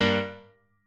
admin-leaf-alice-in-misanthrope/piano34_6_000.ogg at a8990f1ad740036f9d250f3aceaad8c816b20b54